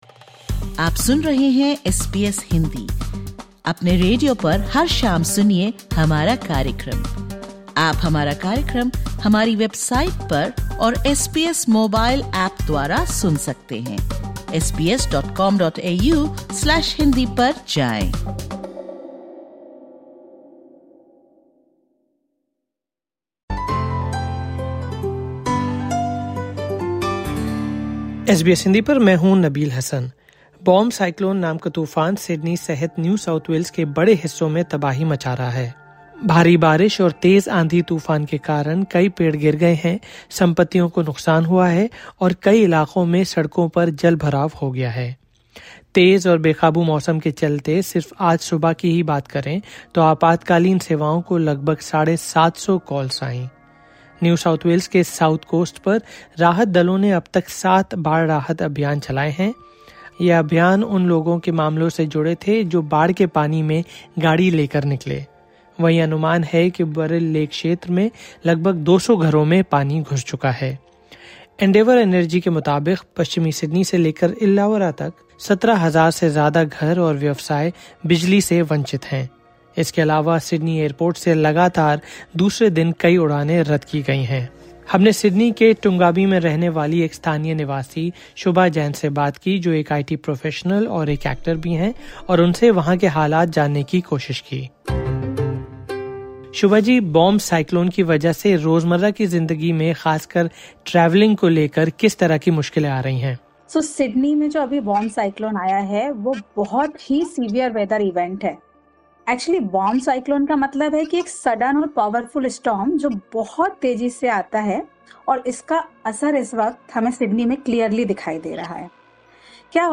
In particular, many areas of Sydney have been severely impacted by heavy rain, strong winds, and flooding, while relief efforts are ongoing. In this SBS Hindi podcast, we provide information about the cyclone and feature conversations with local residents to understand the situation on the ground.